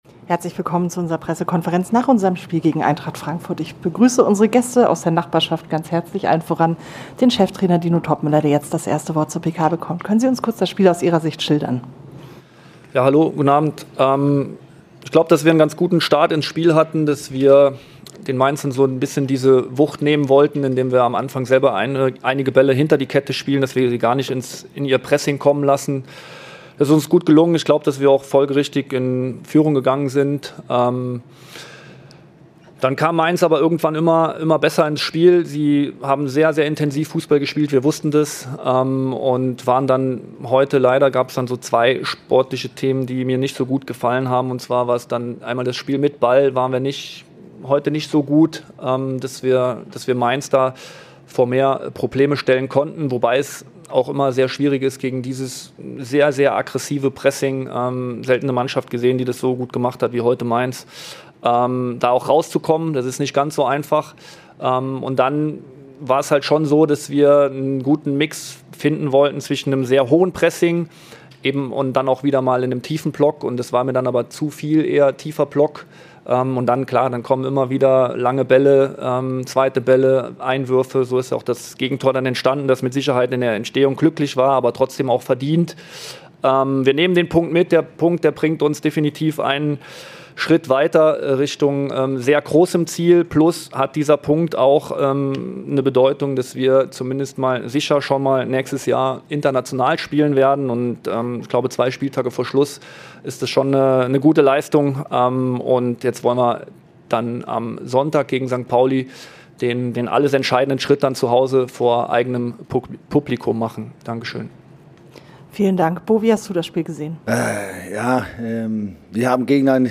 Die Pressekonferenz mit beiden Cheftrainern nach dem Auswärtsspiel in Mainz.